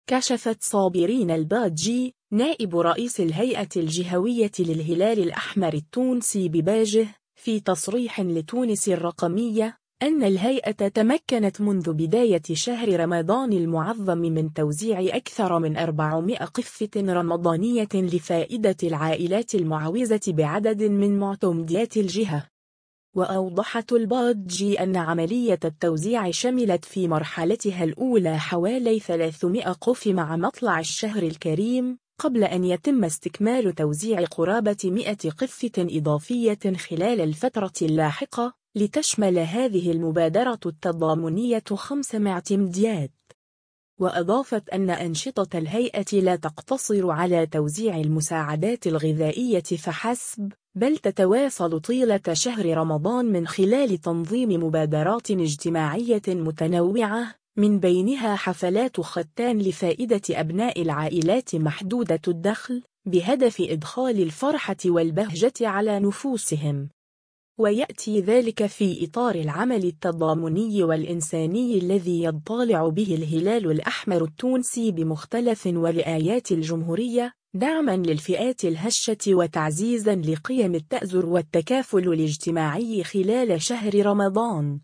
في تصريح لتونس الرقمية